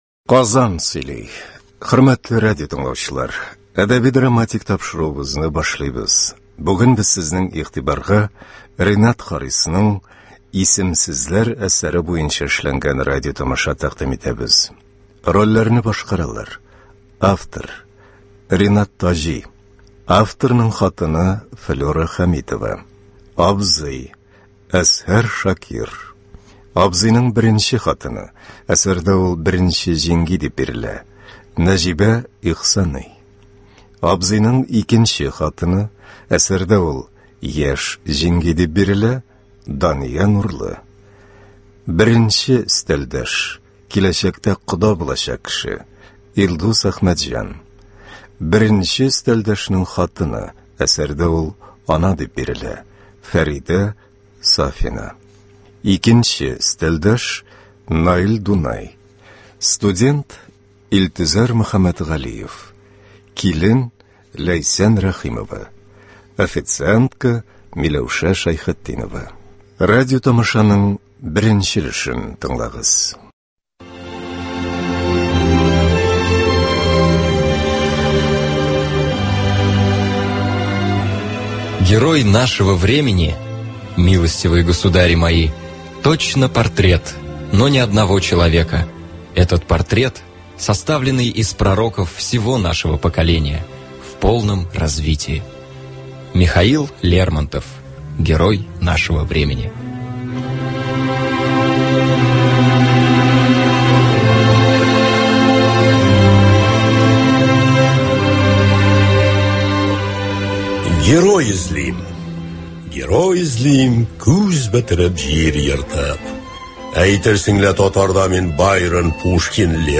Радиотамаша.